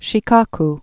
(shē-kôk, shēkô-k)